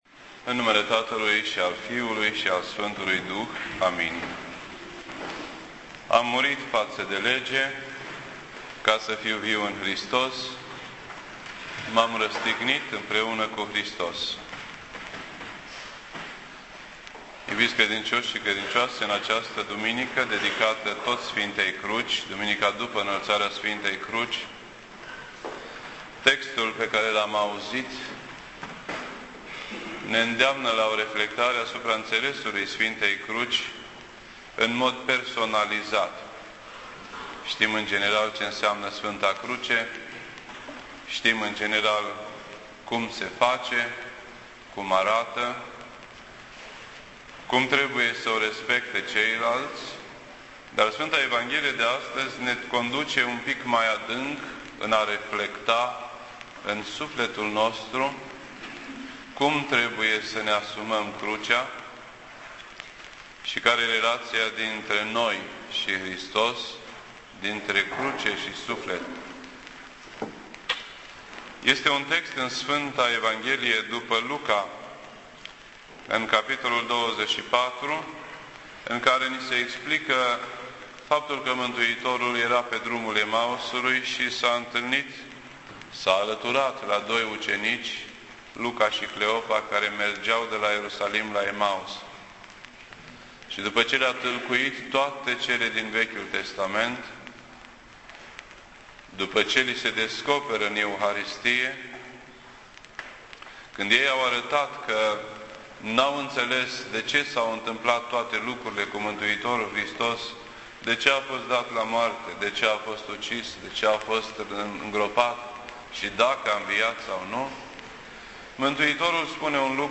This entry was posted on Sunday, September 20th, 2009 at 6:36 PM and is filed under Predici ortodoxe in format audio.